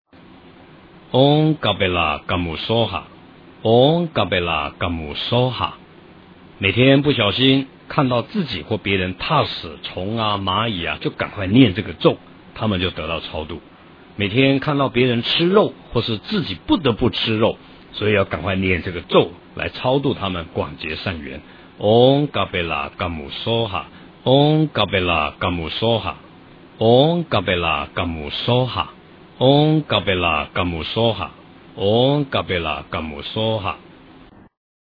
诵经
佛音 诵经 佛教音乐 返回列表 上一篇： 财宝天王心咒 下一篇： 大宝广博楼阁善住秘密陀罗尼咒 相关文章 职场14人该懒散还是精进--佛音大家唱 职场14人该懒散还是精进--佛音大家唱...